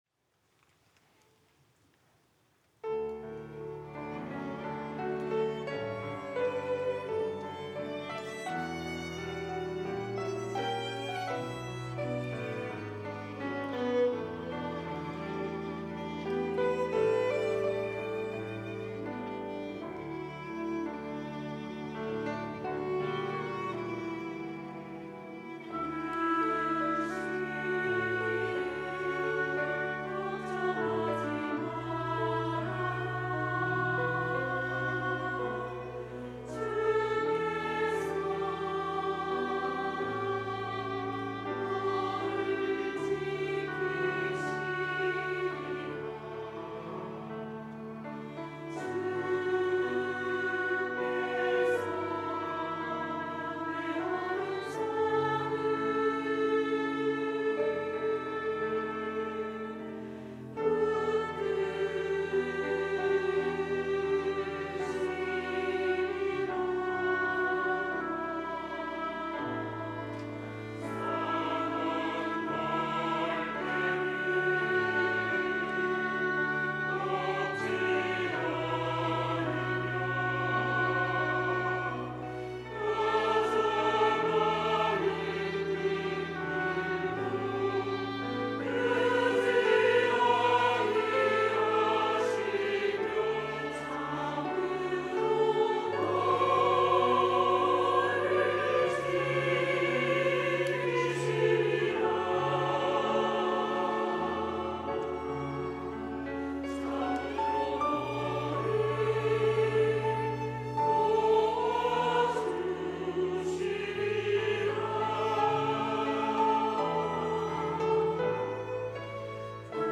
호산나(주일3부) - 너를 지키시리라
찬양대